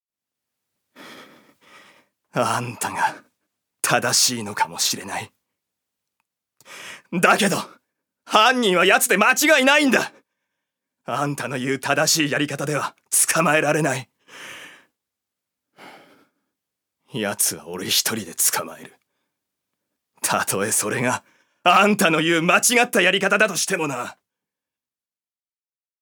所属：男性タレント
セリフ３